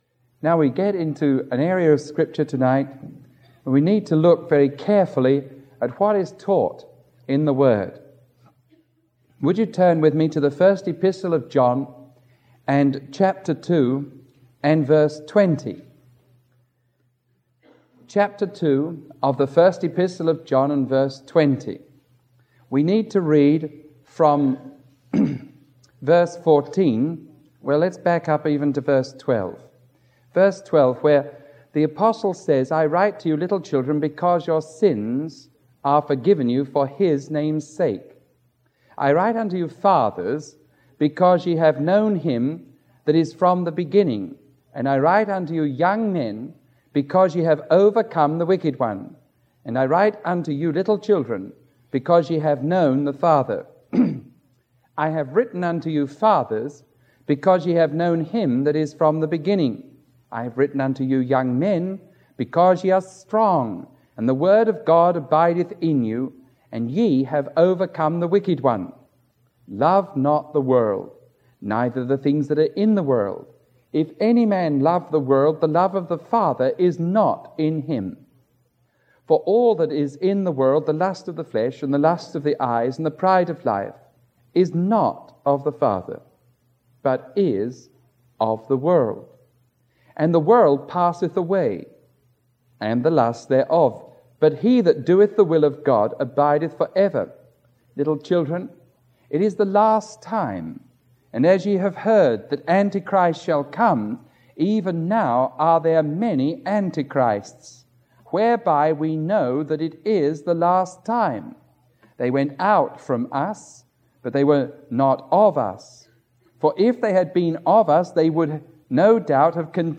Series: Watsonville